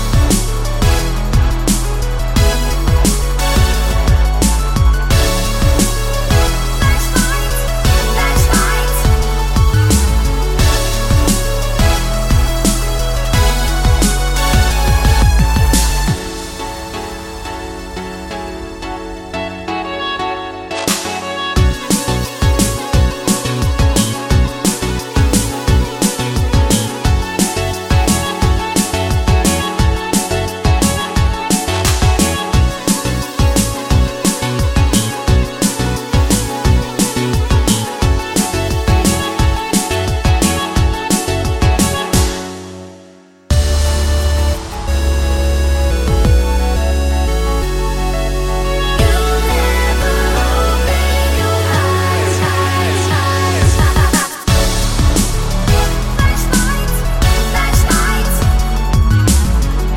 no Backing Vocals Dance 3:33 Buy £1.50